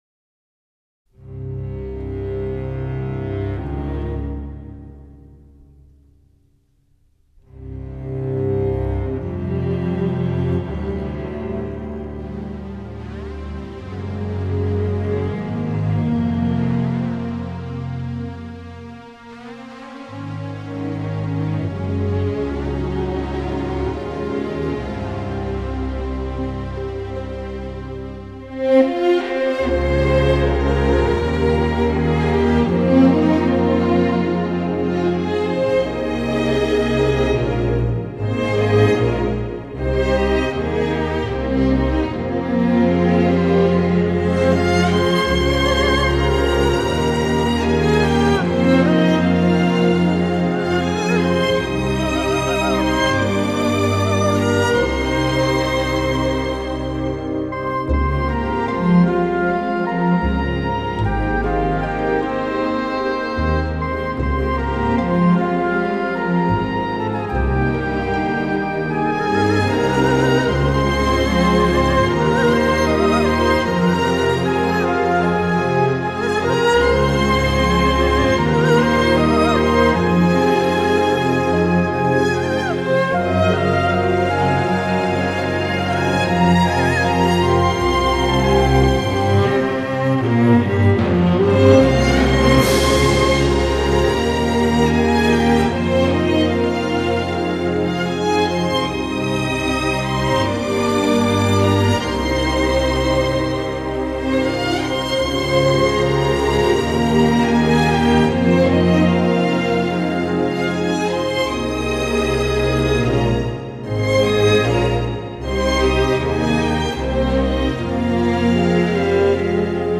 并亲自担纲钢琴演奏。
贯穿整部组曲的主题部分浑厚苍茫，意蕴深远，听之则广阔而神秘的
亢或低婉，或咄咄逼人或静若止水，其表现力如此深广，实在令人叹服。
壮，况味固然有异，精神却是统一，同样相当出彩。